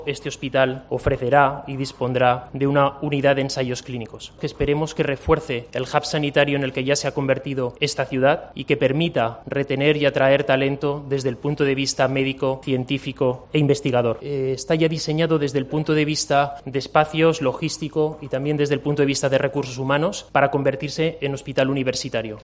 en la colocación de la primera piedra del nuevo hospital.